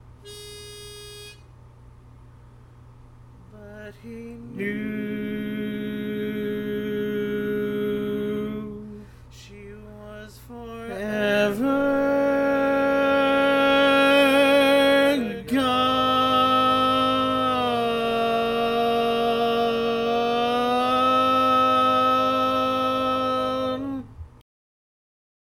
Key written in: A♭ Minor
How many parts: 4
Type: Barbershop